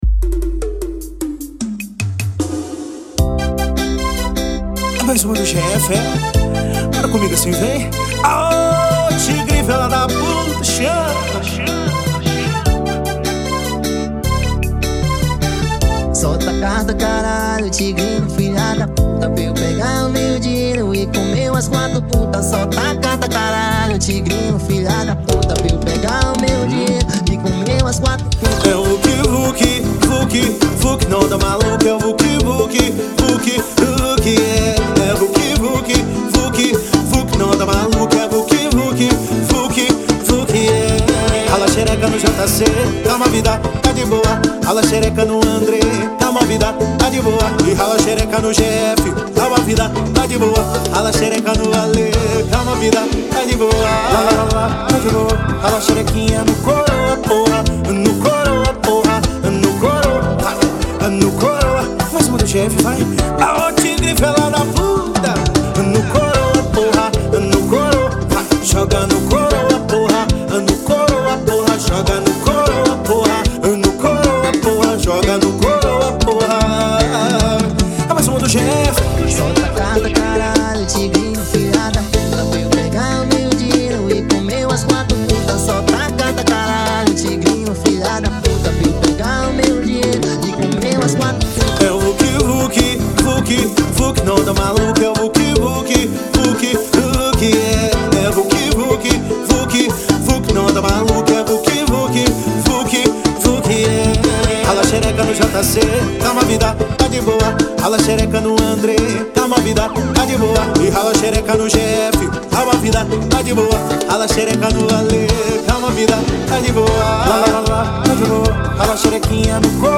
EstiloPiseiro